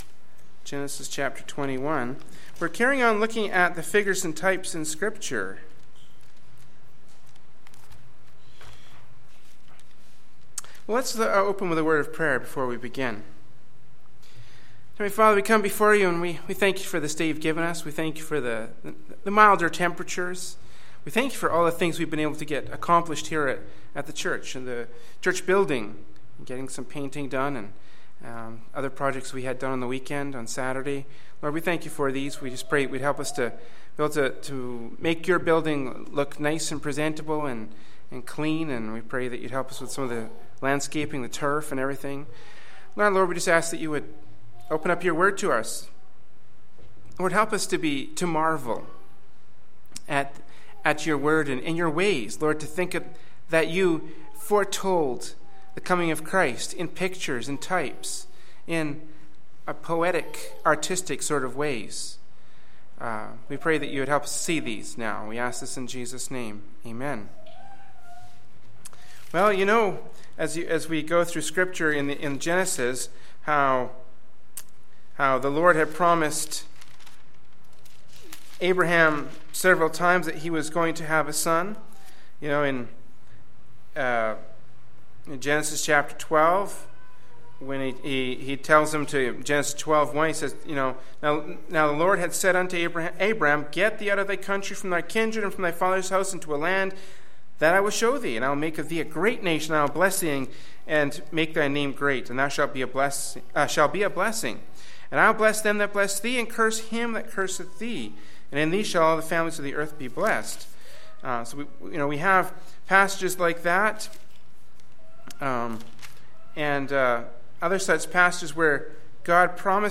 Prayer Meeting